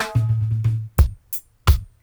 88-FILL-DRY.wav